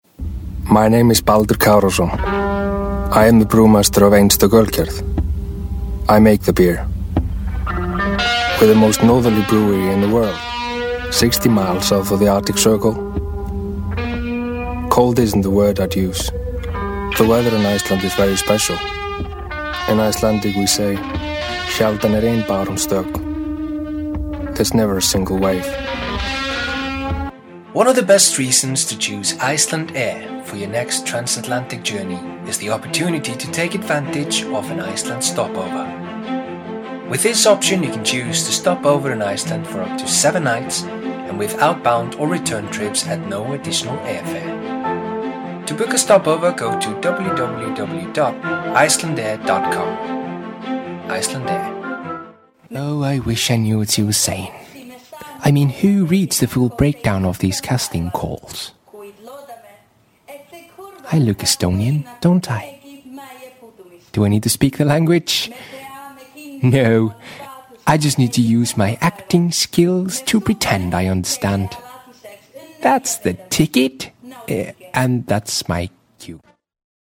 Native speaker Male 20-30 lat
Nagranie lektorskie